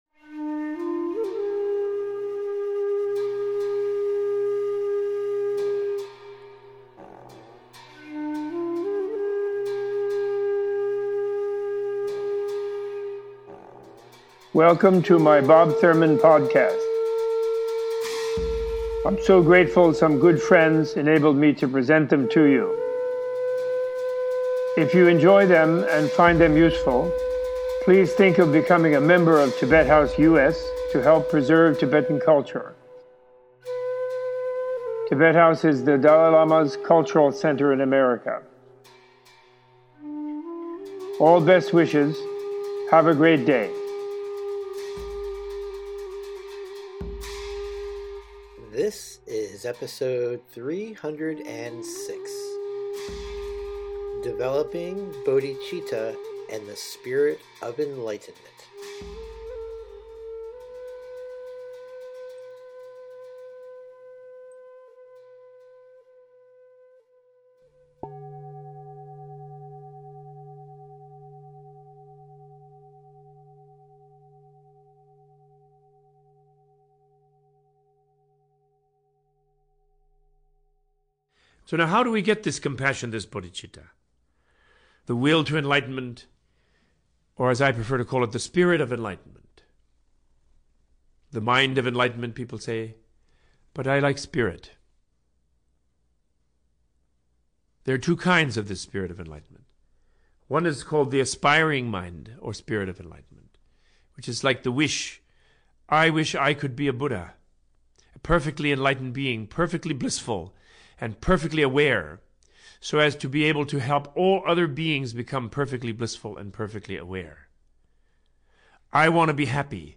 Opening with an introduction to the two kinds of Bodhichitta, Robert Thurman gives an in-depth teaching on wisdom, compassion, love and their connection to the Buddhist understanding of emptiness. This podcast includes a discussion of nirvana and the emanation body (nirmanakaya) which is the form of the Buddha that appears in the world to teach people the path to liberation.